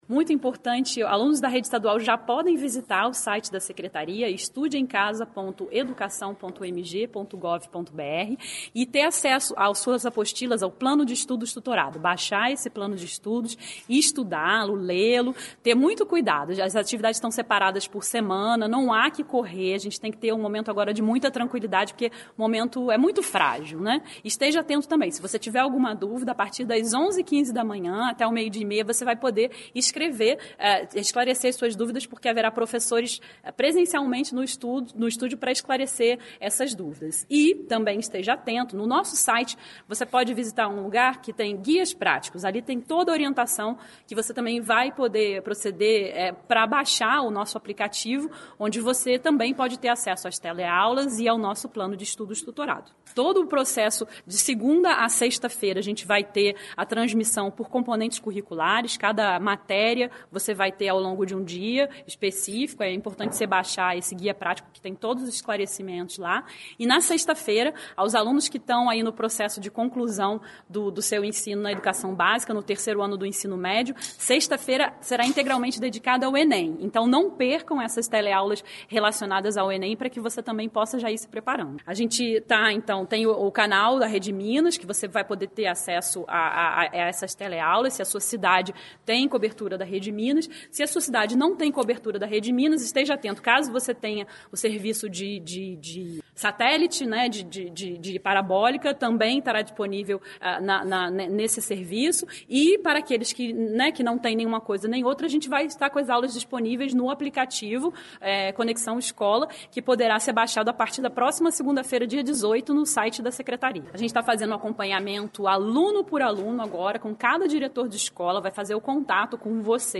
secretária de estado de educação Julia Sant´Anna
secretária de estado de educação Julia Sant´Anna explica sobre o início do regime de estudos não presencial na rede ensino.